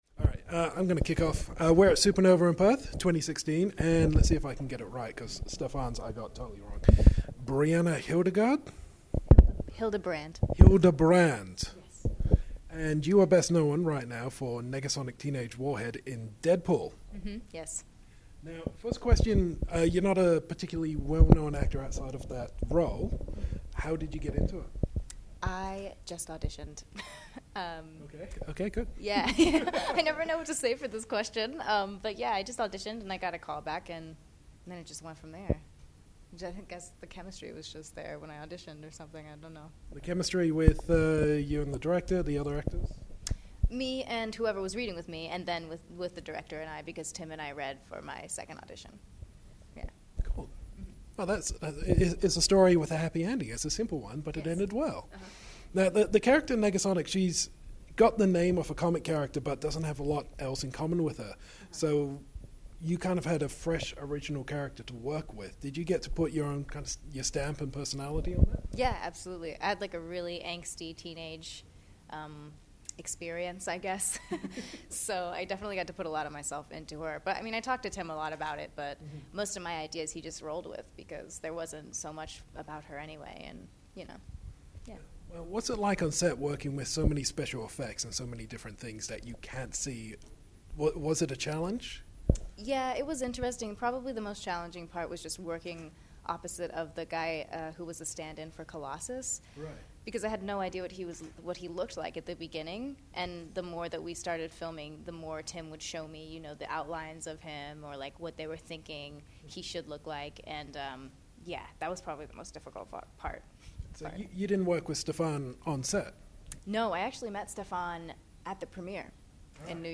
Exclusive Interview with Brianna Hildebrand!
Category : Convention, Interviews
brianna-hildebrand-interview.mp3